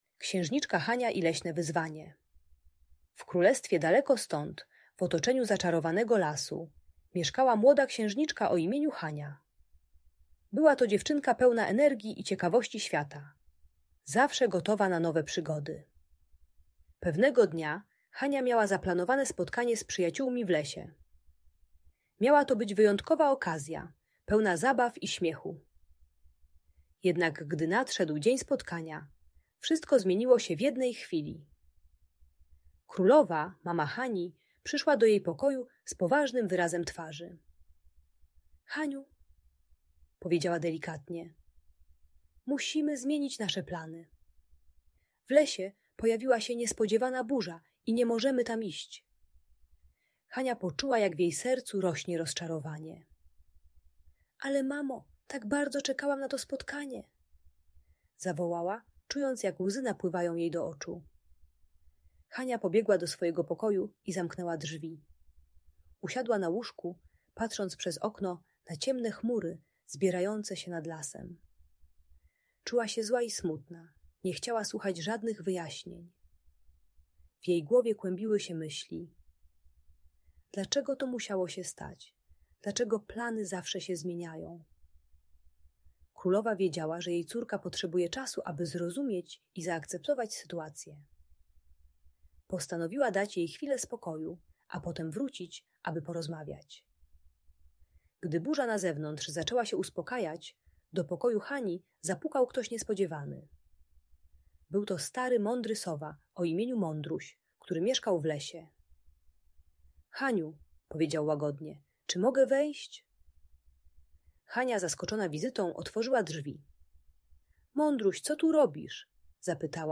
Księżniczka Hania i Leśne Wyzwanie - Bunt i wybuchy złości | Audiobajka